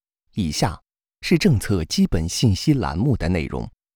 Chinese_Male_005VoiceArtist_20Hours_High_Quality_Voice_Dataset
Text-to-Speech